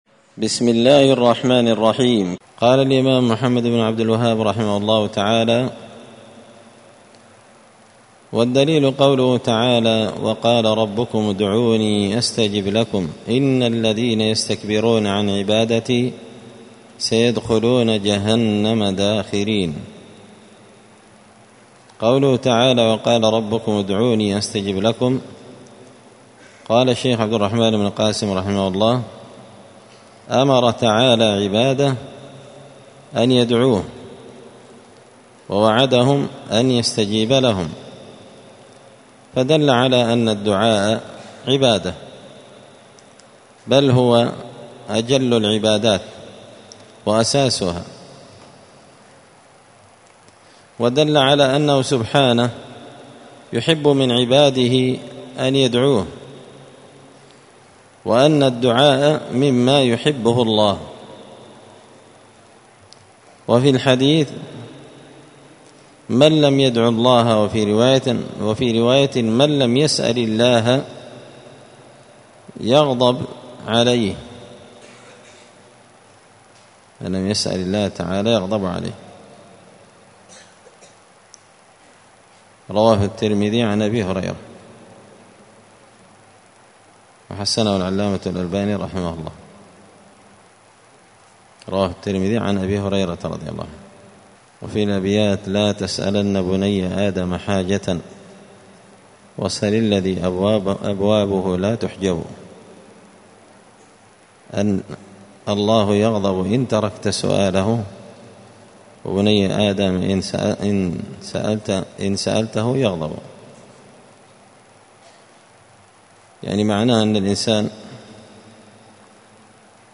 *الدرس الرابع عشر (14) من قوله {والدعاء هو العبادة والدليل قوله تعالى(وقال ربكم ادعوني…}*